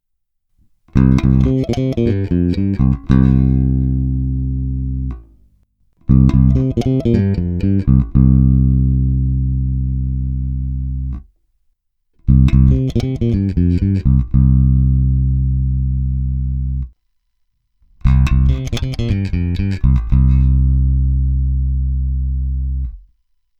Ukázky jsou nahrány rovnou do zvukovky, jednotlivé nahrávky jsou normalizovány, přičemž pasívní režim nebo aktivní režim se staženými (nepřidanými) korekcemi je co do síly signálu daleko slabší než při použití ekvalizéru, což samozřejmě tyto normalizované nahrávky nepostihnou.
Použité struny jsou dva roky staré ocelové pětačtyřicítky Elixiry.
Vliv korekcí na sílu signálu a barvu zvuku – hráno na oba snímače v pořadí: korekce na nule, přidány na 1/3, přidány na 2/3, přidány naplno.
Normalizováno